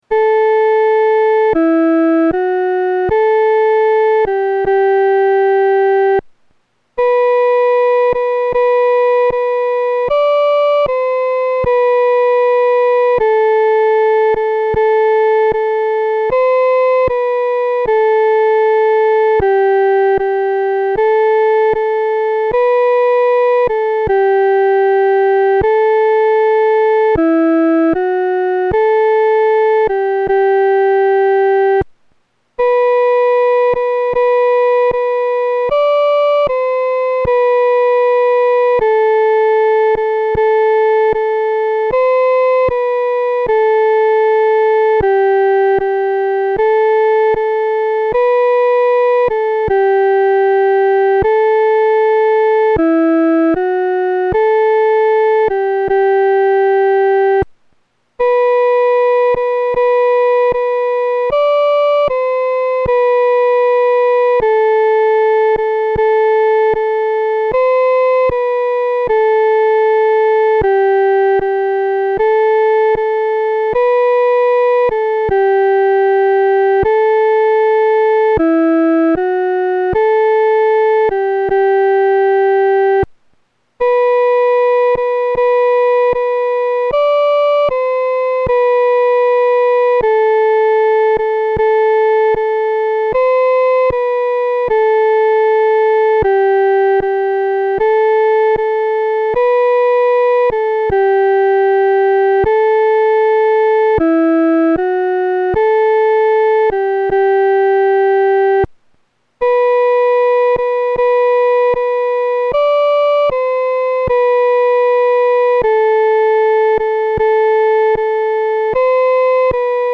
伴奏
女高